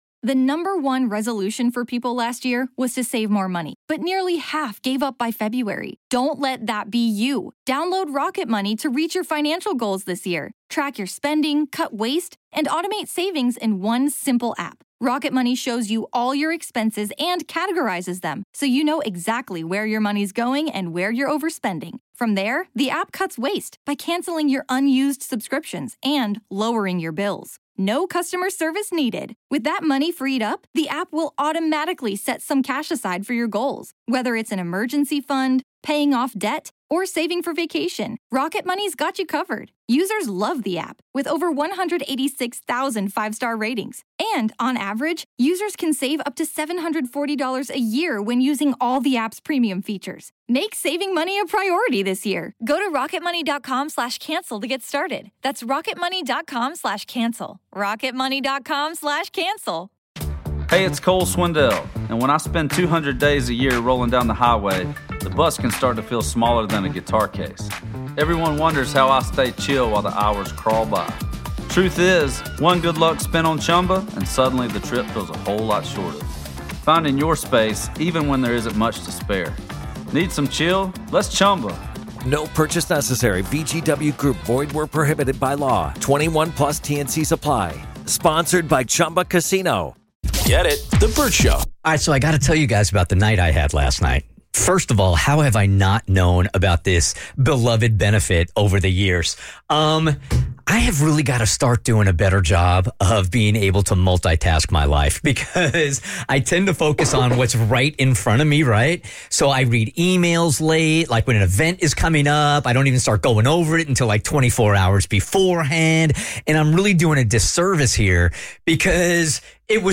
Click here to listen to some behind the scenes Bert Show bloopers that happen off air!